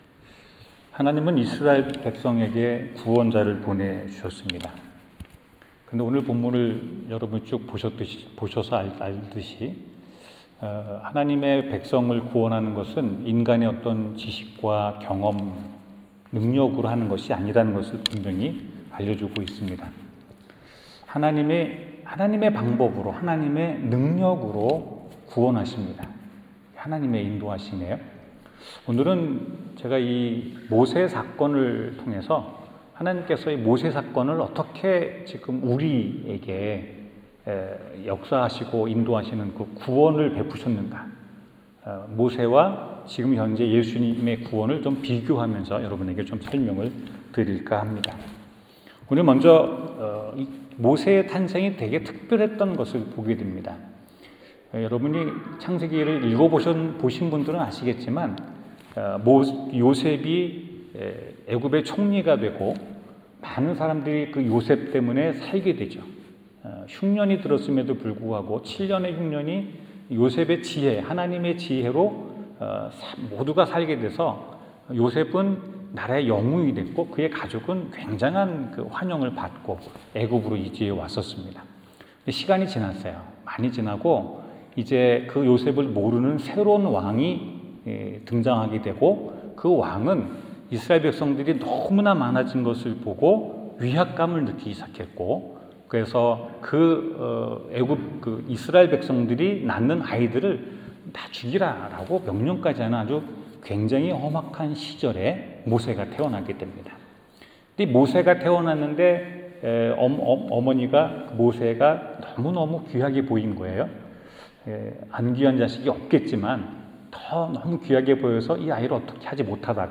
성경 : 사도행전 7장 17-36절 설교